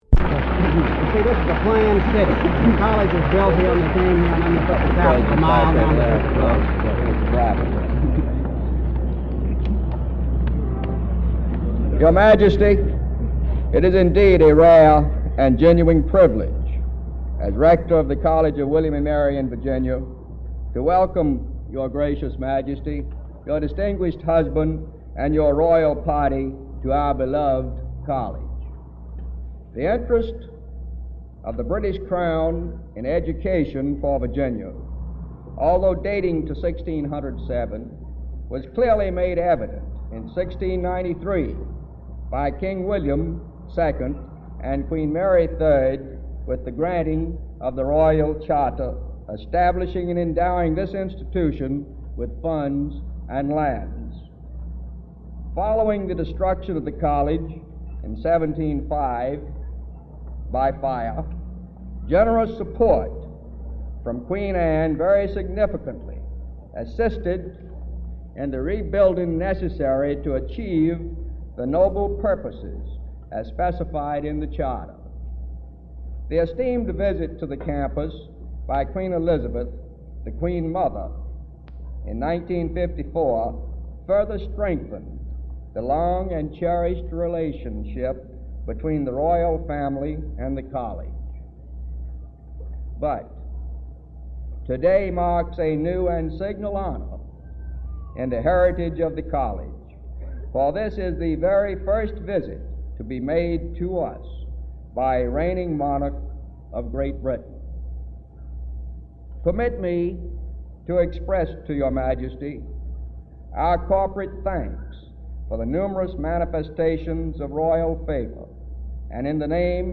Abstract: This audio recording was digitized from one reel-to-reel audiotape of Queen Elizabeth II's visit to The College of William and Mary. Principal speakers were Queen Elizabeth II and Prince Philip. Tape length is approximately 30 minutes with tape speed 3 3/4ips.